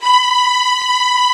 Index of /90_sSampleCDs/Roland LCDP13 String Sections/STR_Combos 2/CMB_Str.Orch Oct